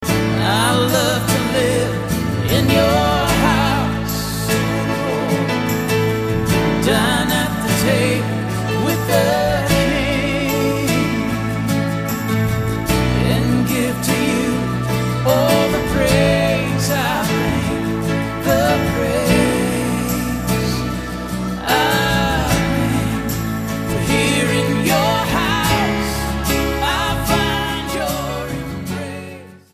STYLE: Pop
performing nicely arranged, anthemic rock-gospel.